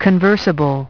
Transcription and pronunciation of the word "conversable" in British and American variants.